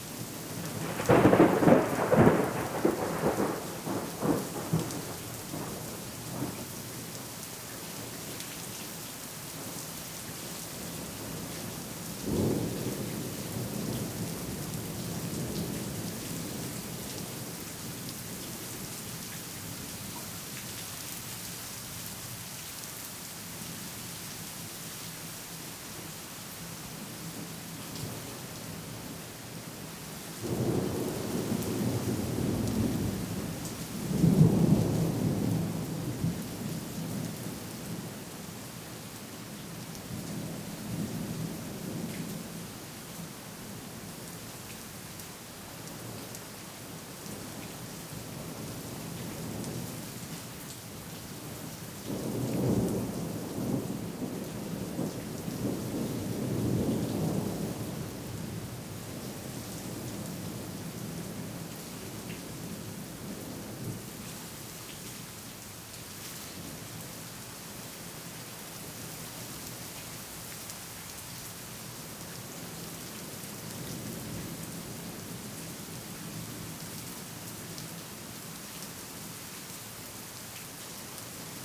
Weather Evening Medium Rain Thunder Claps Rustling Trees ST450 03_ambiX.wav